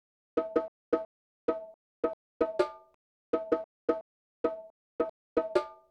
• bongos and congas resample 5.wav
bongos_and_congas_sample_5_CeL.wav